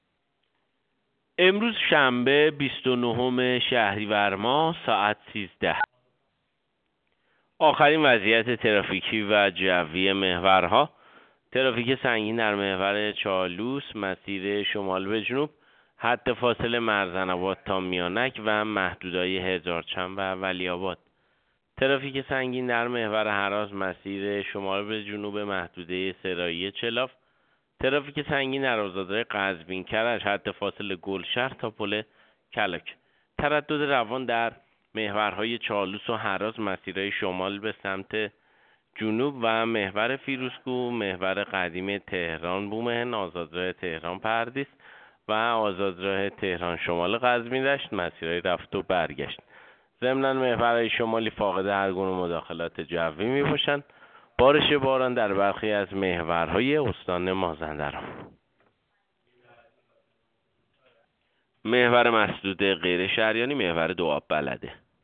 گزارش رادیو اینترنتی از آخرین وضعیت ترافیکی جاده‌ها ساعت ۱۳ بیست و نهم شهریور؛